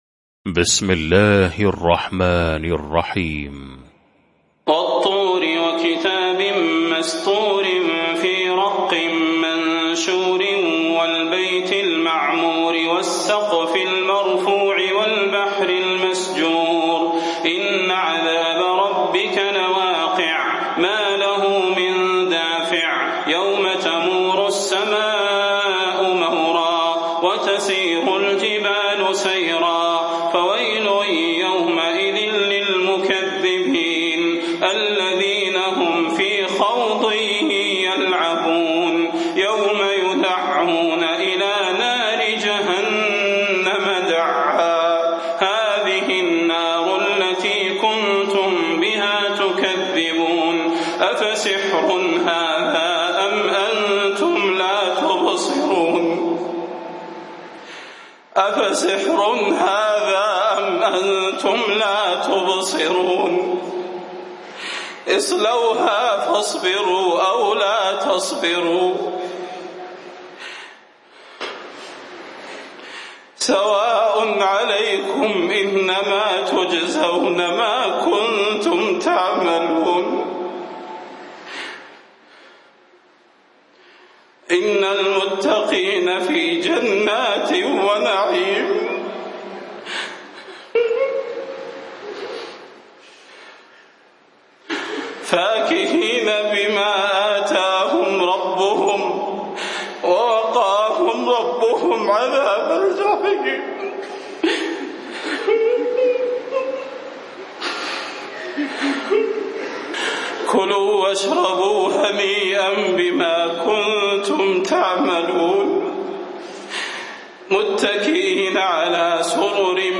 المكان: المسجد النبوي الشيخ: فضيلة الشيخ د. صلاح بن محمد البدير فضيلة الشيخ د. صلاح بن محمد البدير الطور